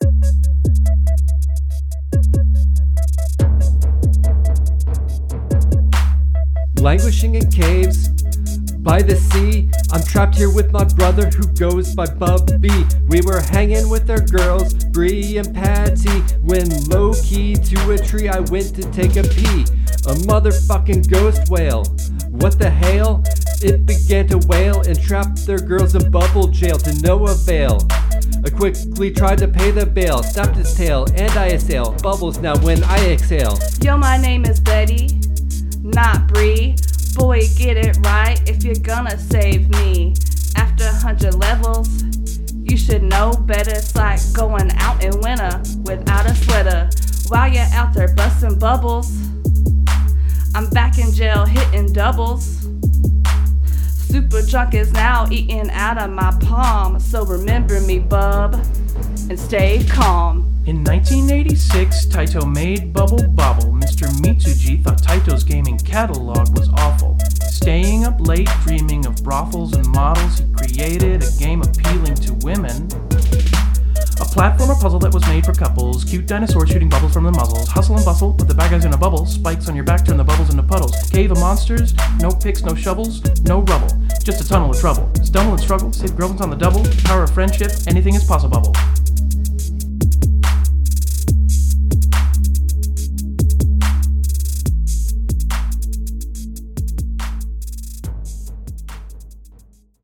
Rap from Episode 10: Bubble Bobble – Press any Button
bubble-bobble-rap.mp3